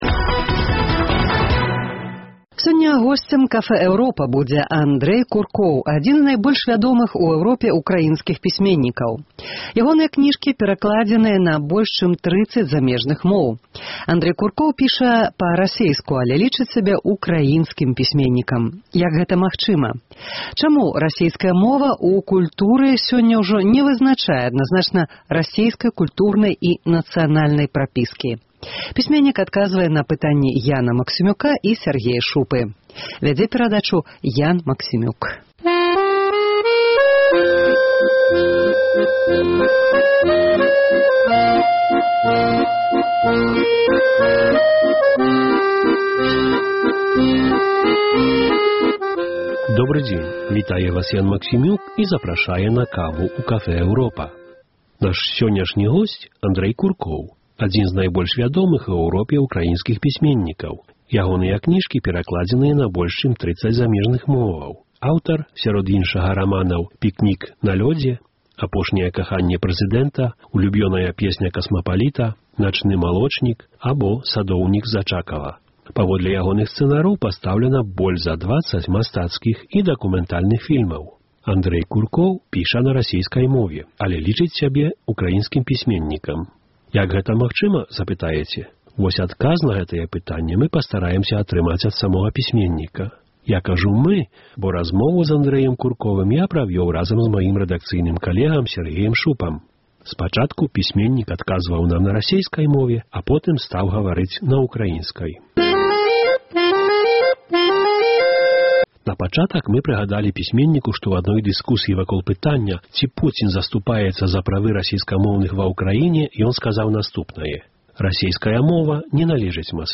Госьць Cafe Europa– Андрэй Куркоў, адзін з найбольш вядомых у Эўропе украінскіх пісьменьнікаў.